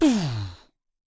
家庭制作的节奏 " 人肉拍打盒
描述：一个人的拍子箱奠定了一个节奏。
标签： 声乐 节奏 节拍盒 自制
声道立体声